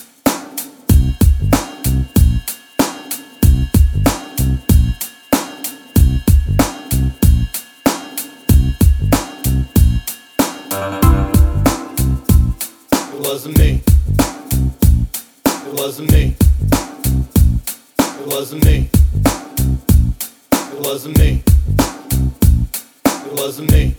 no Backing Vocals Reggae 3:47 Buy £1.50